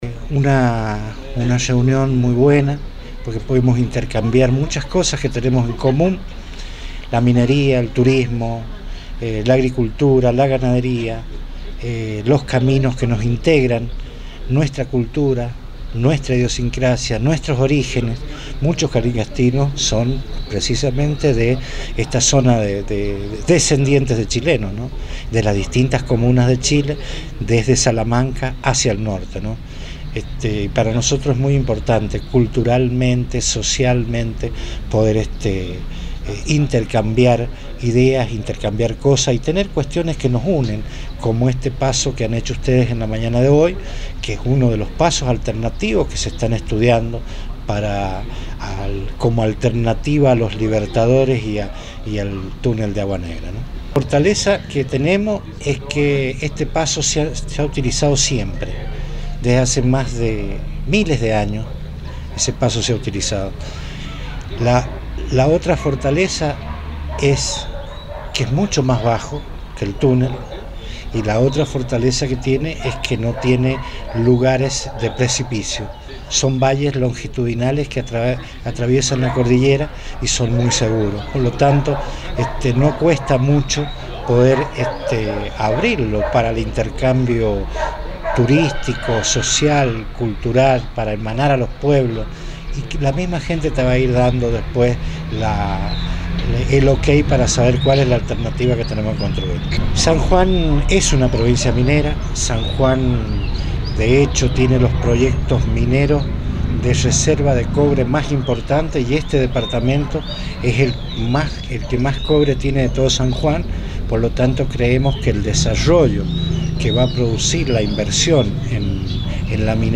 Luego de una reunión sostenida entre las autoridades locales  con la delegación salamanquina, Jorge Castañeda, Intendente de Calingasta, señaló: